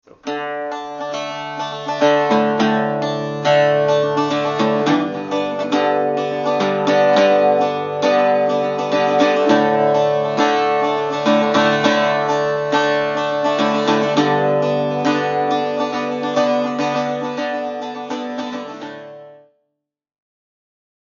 Irish Bouzouki Lessons Online | Learn the Bouzouki - OAIM
Bouzouki-Basics-1.mp3